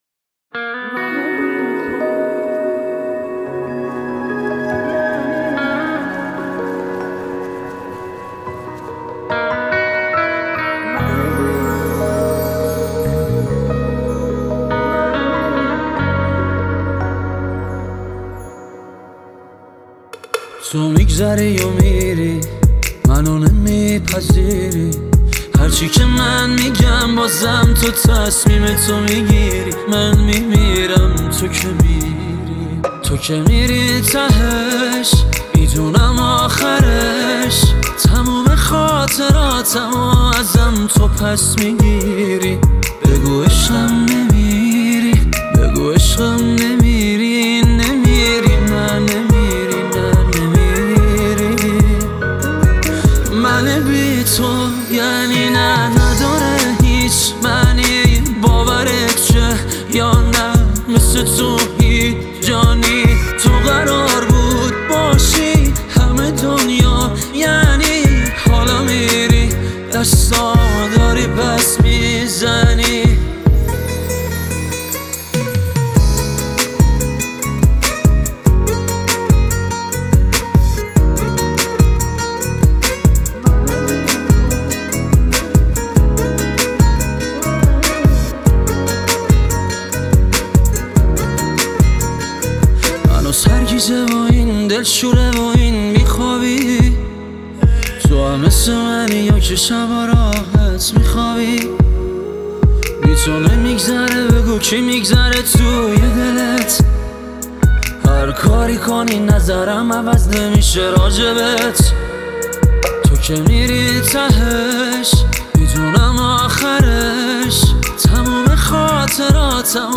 غمگین, پاپ